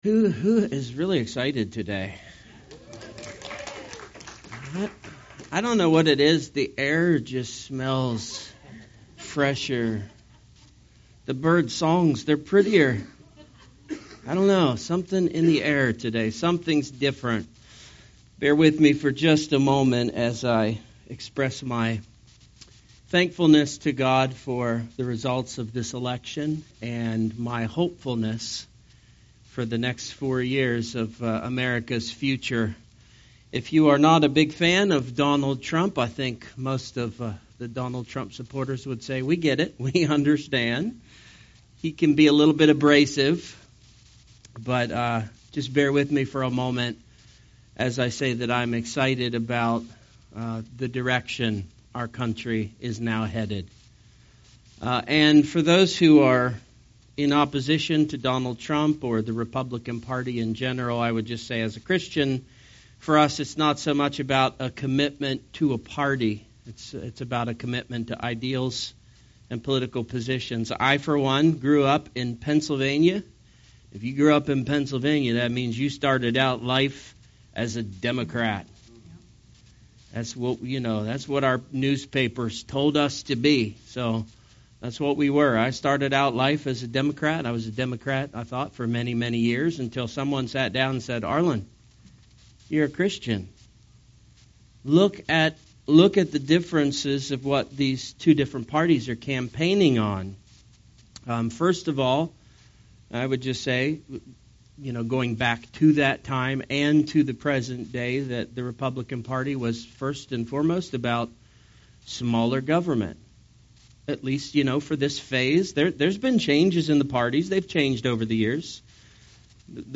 In this section of our service, we discussed our Church Relocation Plans and our intentions to become an associate of Calvary Chapel.